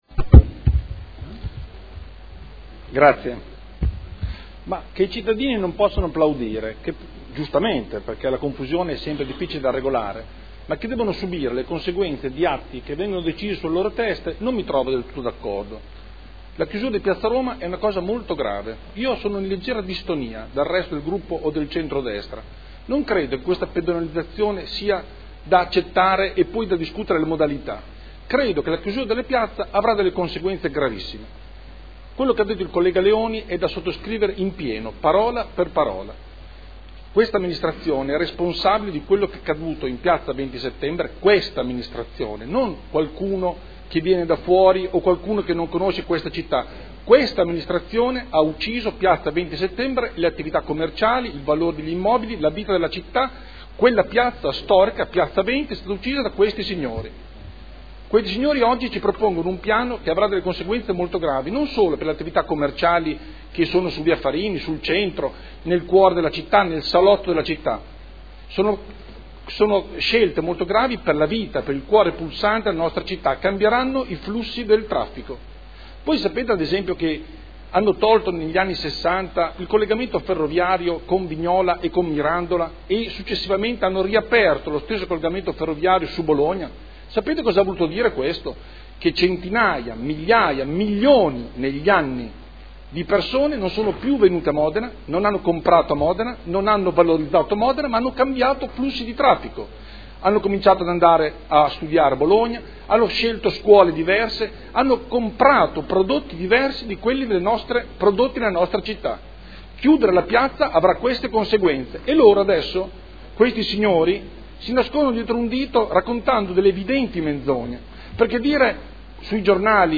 Andrea Galli — Sito Audio Consiglio Comunale